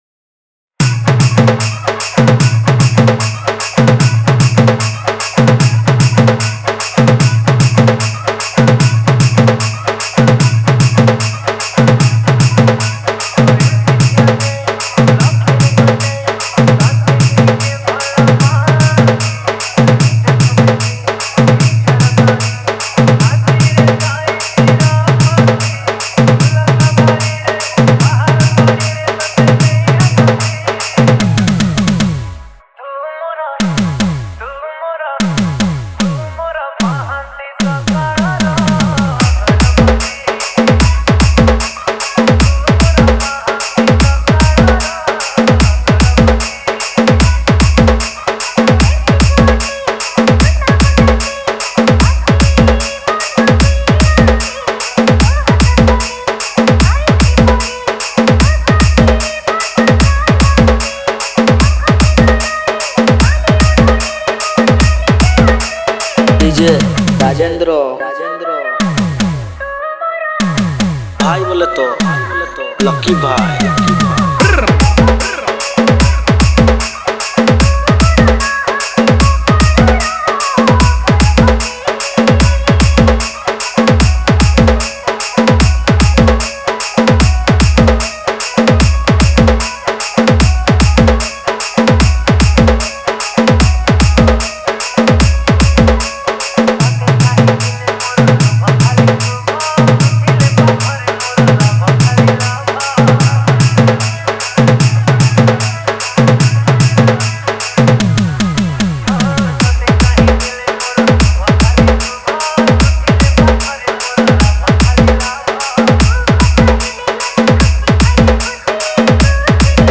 Category:  New Odia Dj Song 2024